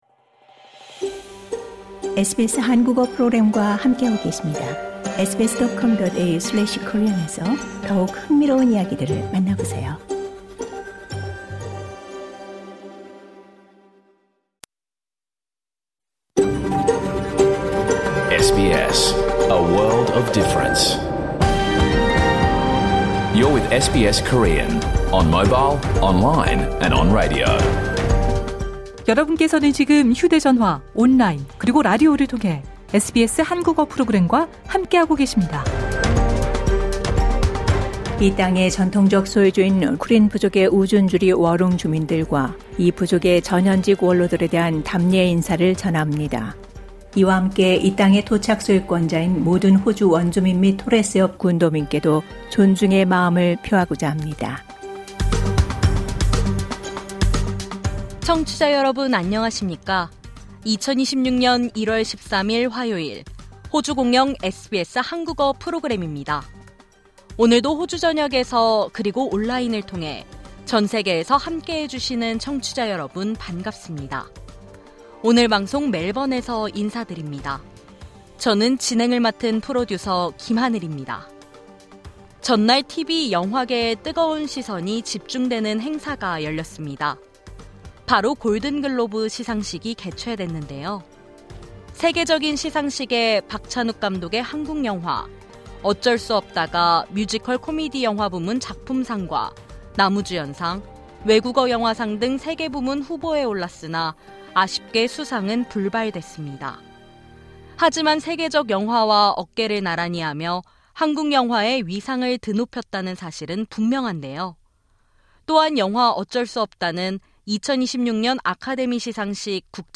2026년 1월 13일 화요일에 방송된 SBS 한국어 프로그램 전체를 들으실 수 있습니다.